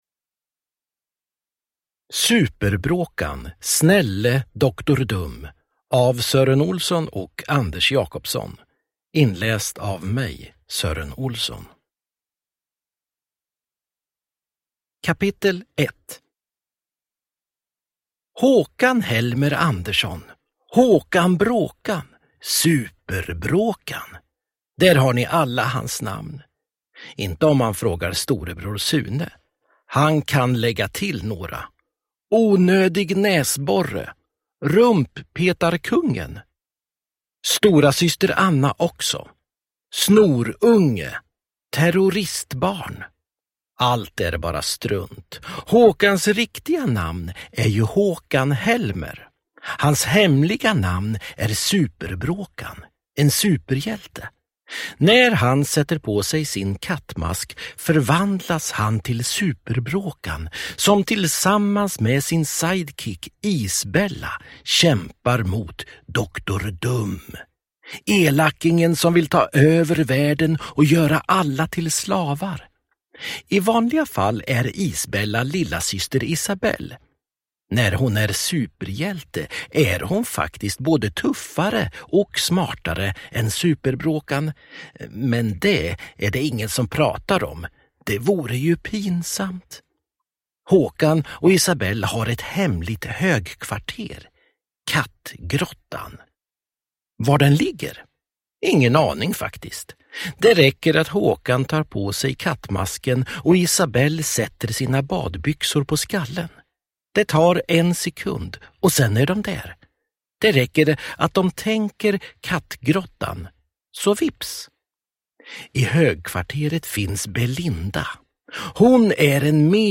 Super-Bråkan. Snälle Doktor Dum – Ljudbok
Uppläsare: Sören Olsson